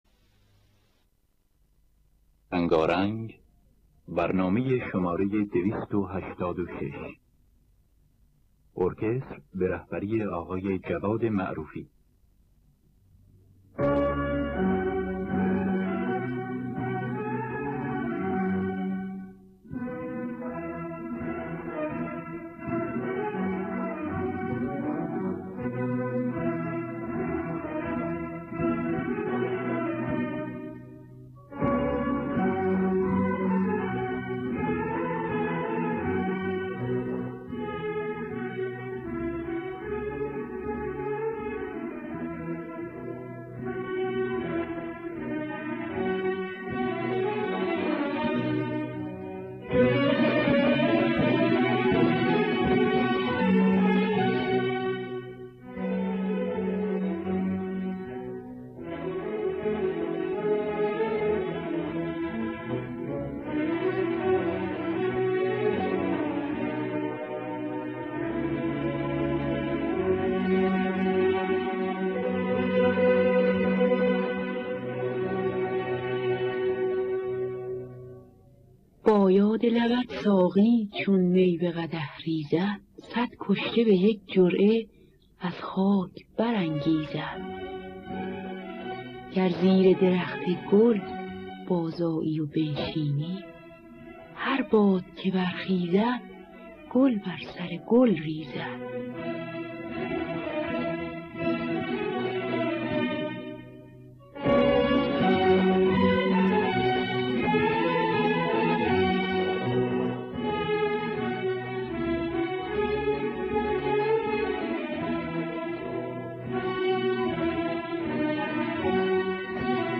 دانلود گلهای رنگارنگ ۲۸۶ با صدای مرضیه، اکبر گلپایگانی در دستگاه افشاری.